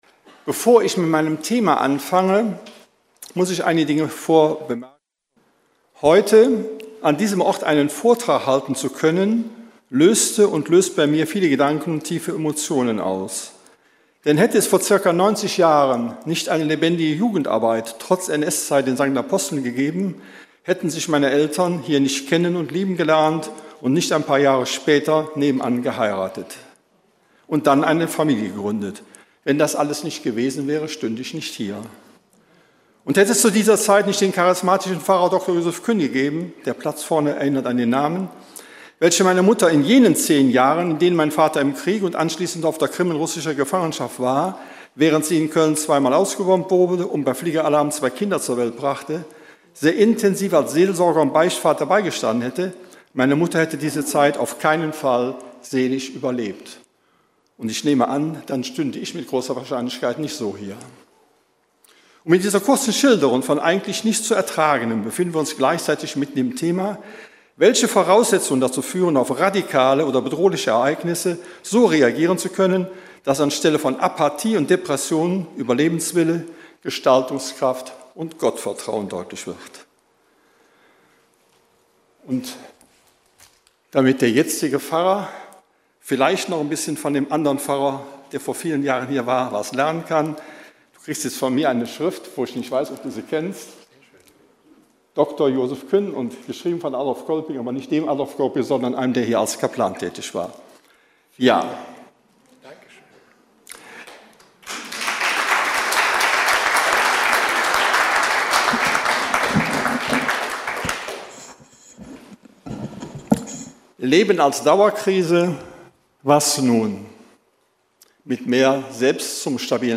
Vorträge herausragender Wissenschaftler, die in Akademien und Bildungswerken Glaube und Welt hinterfragen und erklären.